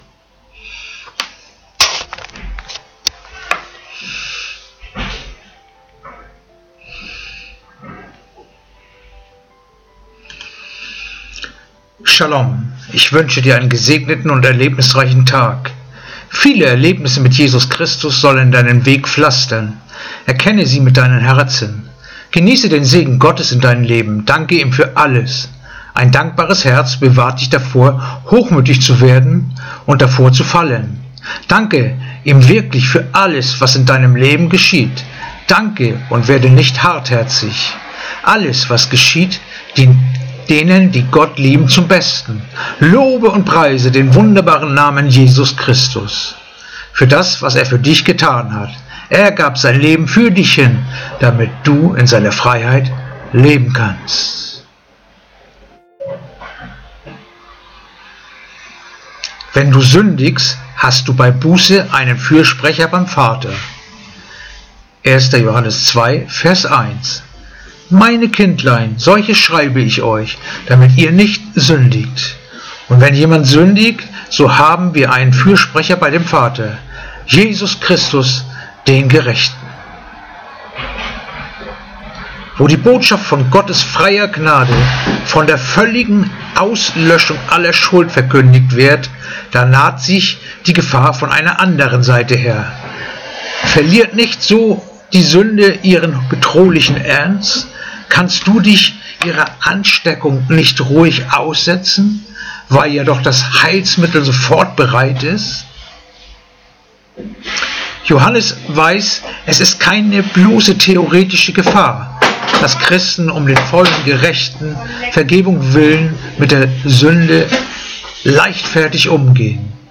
Andacht-vom-09-April-1-Johannes-2-1